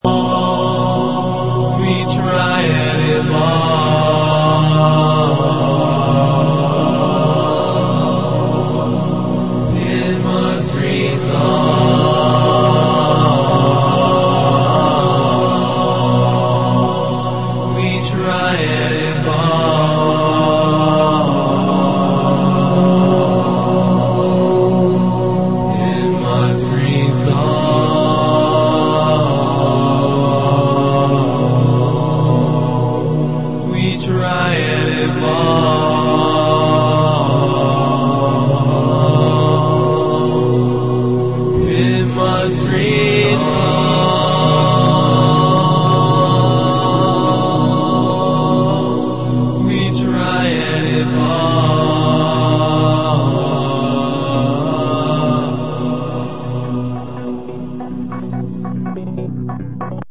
6 firefly constellations Electronix Indie